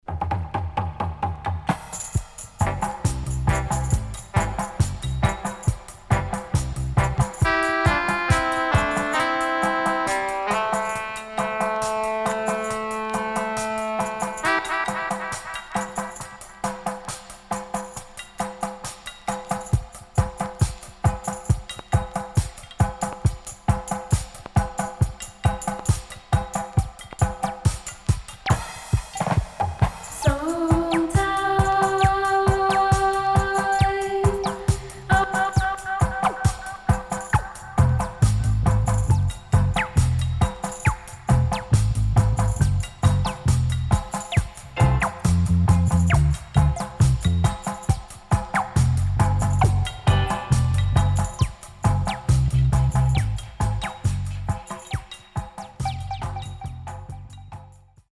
CONDITION SIDE A:VG(OK)〜VG+
Nice Lovers Vocal & Dubwise
SIDE A:うすいこまかい傷ありますがノイズあまり目立ちません。